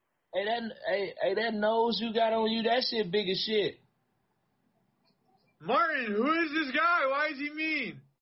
yo who is this guy Meme Sound Effect